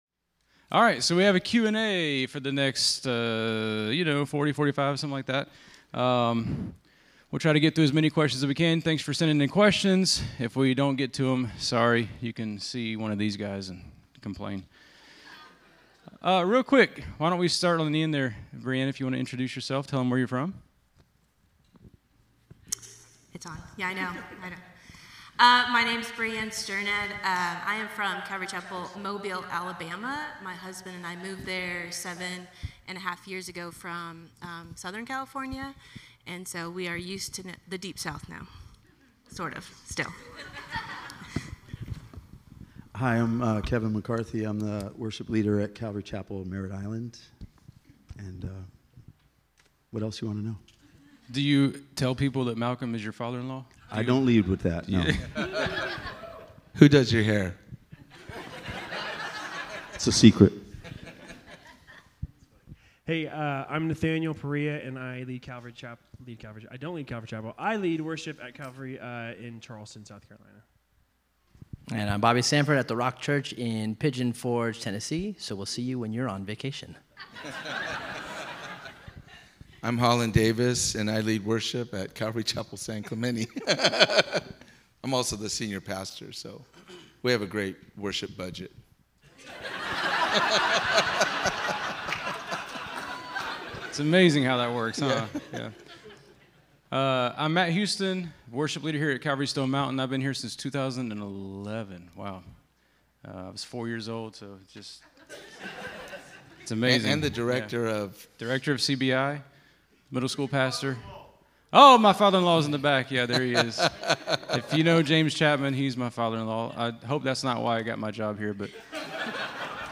Q&A Panel Discussion
Conference: Worship Conference